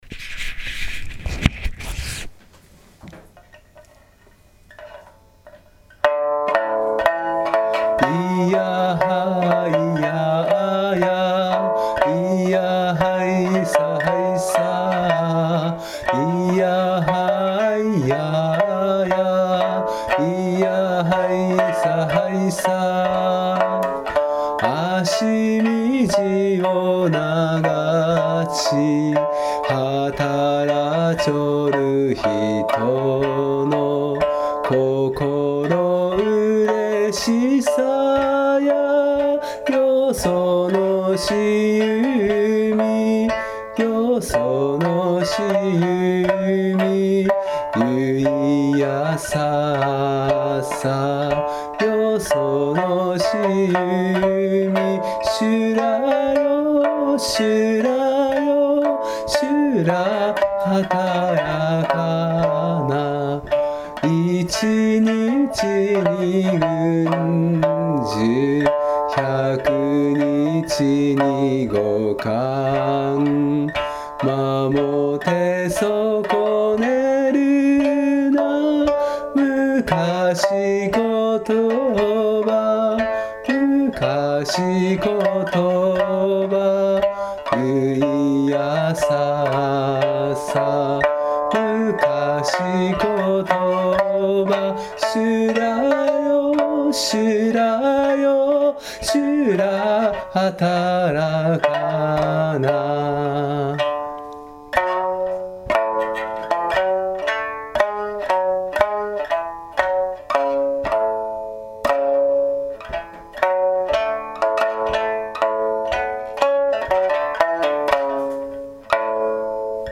そんな訳で三線でちょっと歌って見たいと思います。